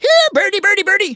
tnt_guy_start_vo_02.wav